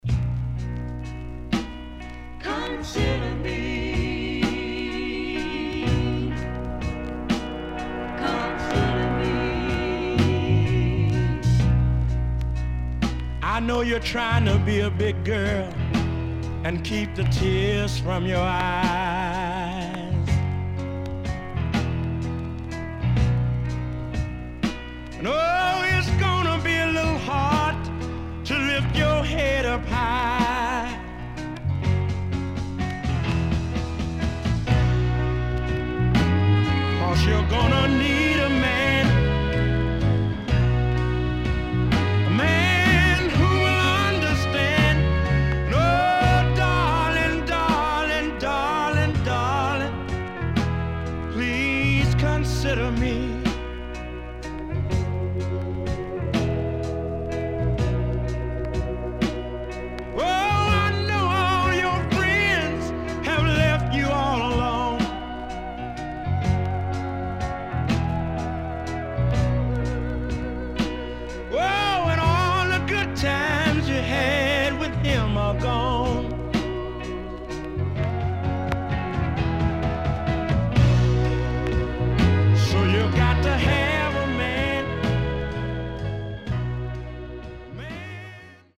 CONDITION SIDE A:VG(OK)〜VG+
SIDE A:所々チリノイズ入ります。